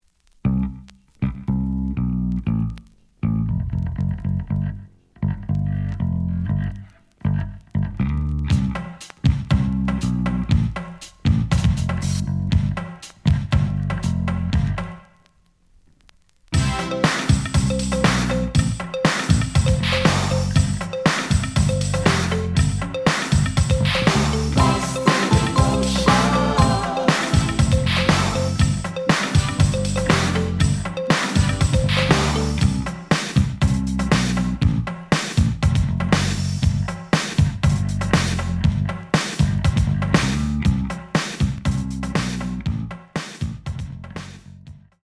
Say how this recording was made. The audio sample is recorded from the actual item. Slight noise on beginning of both sides, but almost good.